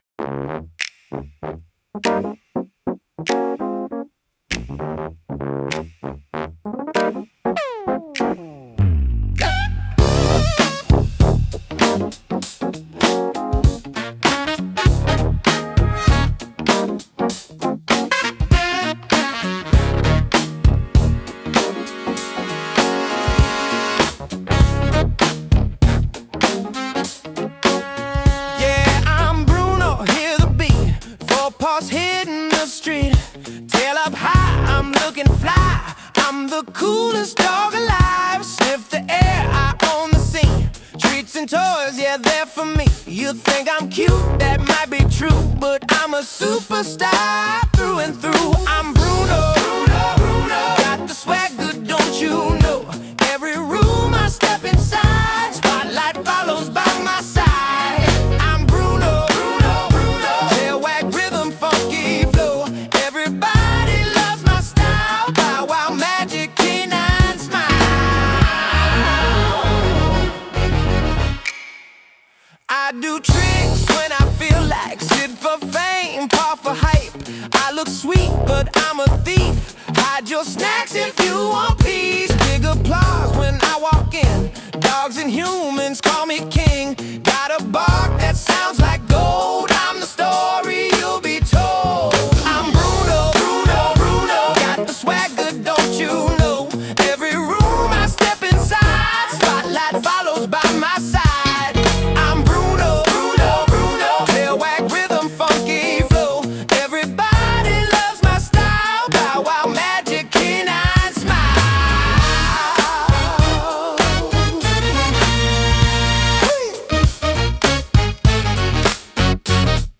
A funky, feel-good song made for a very good boy.